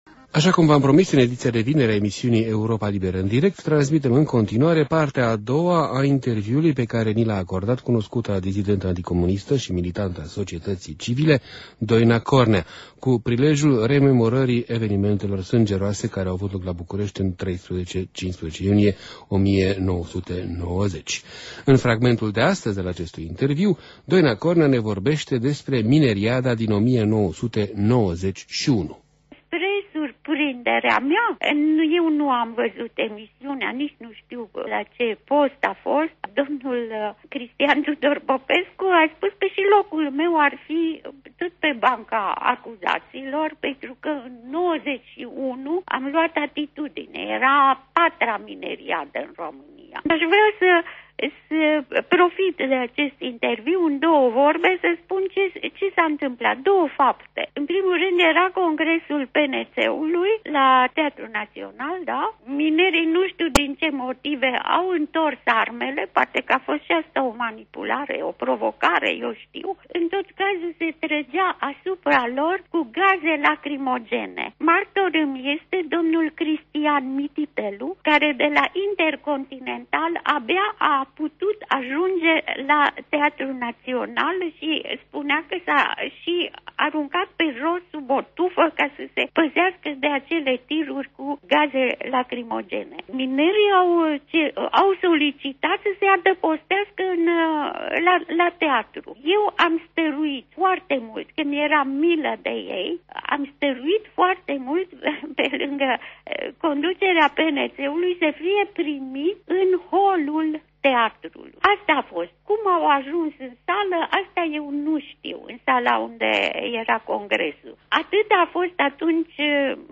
Doina Cornea vorbind Europei Libere în iunie 2005 despre mineriada din 1991, când a fost acuzată de genocid de persoane care nu existau: